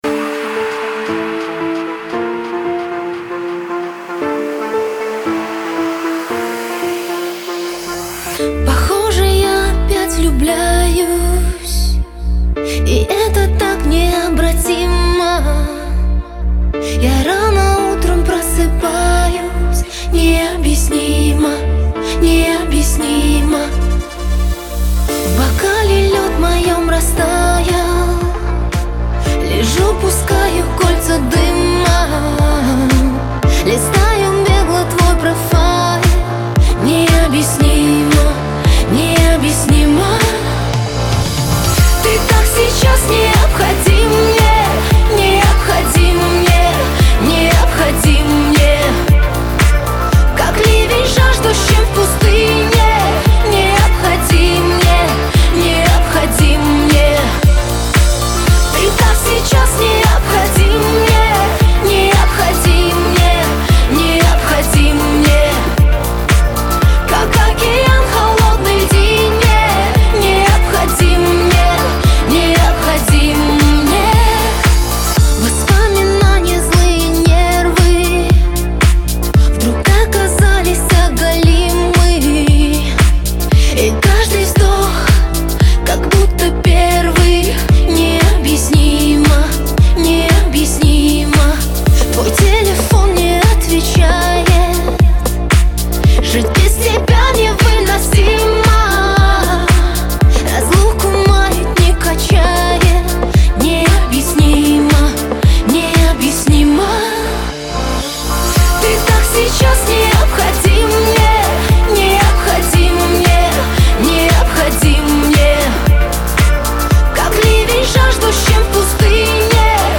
Категория: Поп Музыка